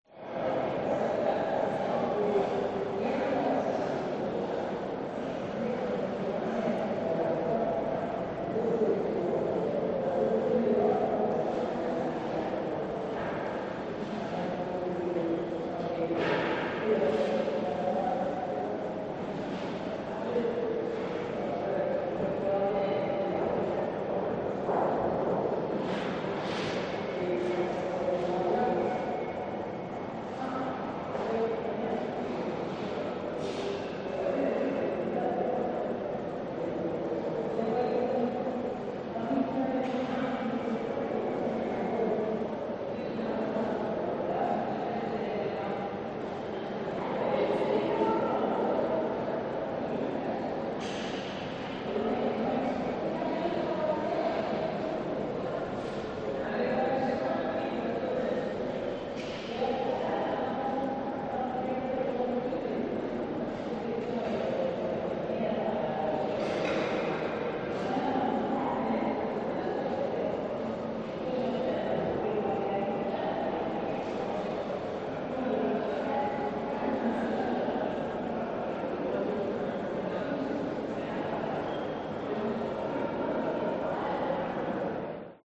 Музейный зал в Стокгольме наполнен жужжанием голосов